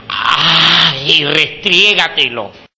knife.wav